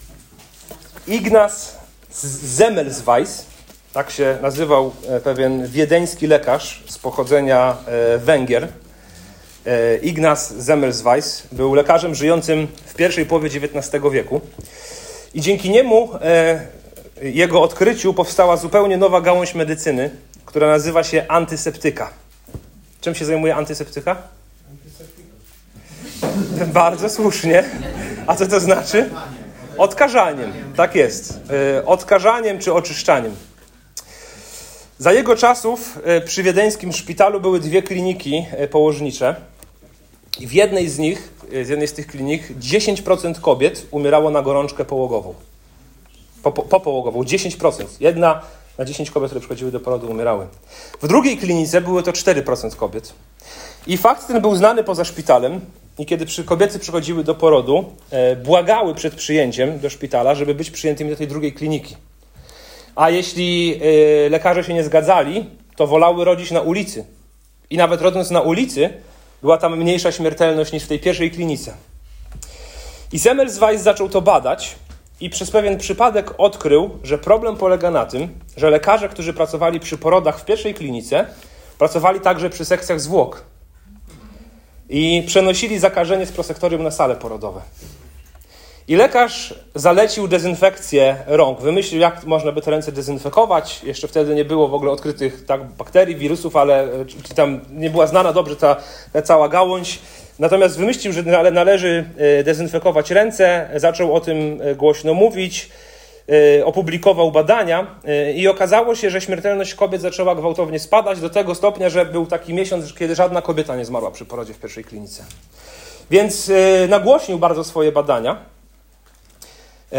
Sanhedryn nie zaprzecza cudom Jezusa, ale postanawia się Go pozbyć. To kazanie konfrontuje nas z pytaniem, czy sami nie uciszamy prawdy, gdy zaczyna zagrażać naszej kontroli i wizerunkowi.